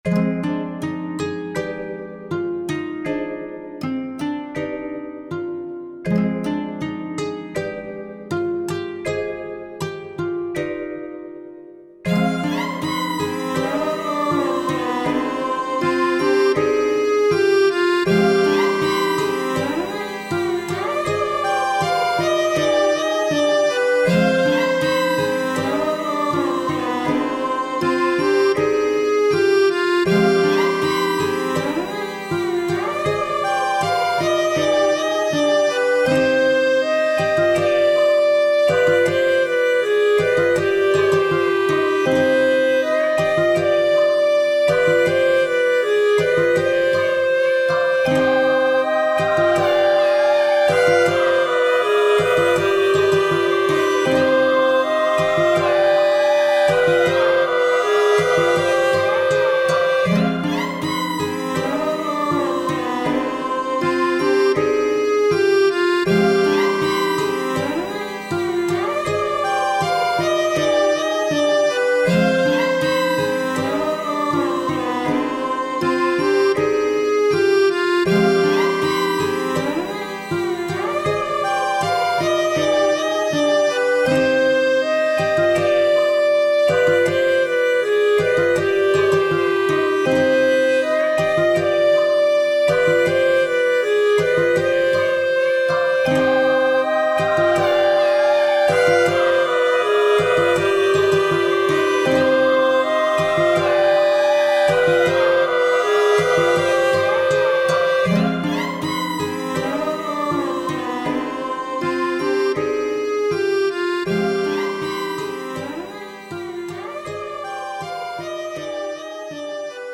タグ: ダラダラ ファンタジー 不思議/ミステリアス 不気味/奇妙 怪しい 暗い コメント: 暗くて怪しい雰囲気の楽曲。